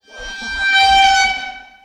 waterphone.wav